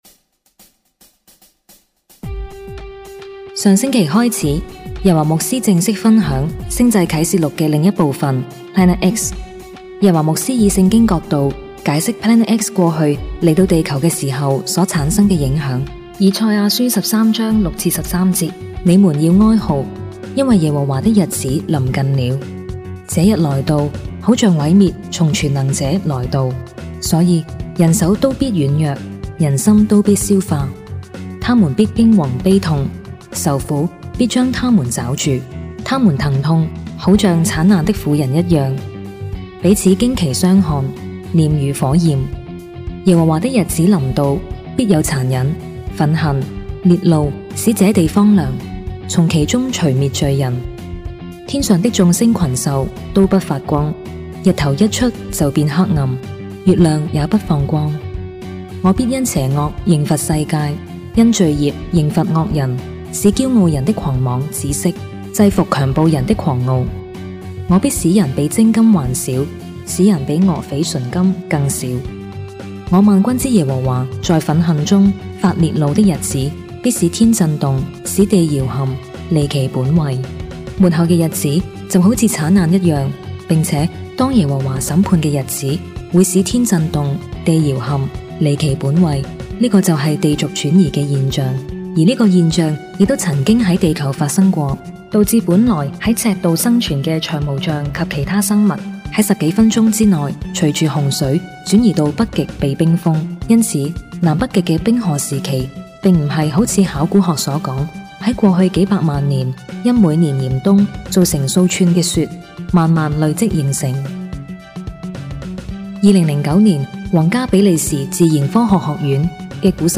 錫安教會主日信息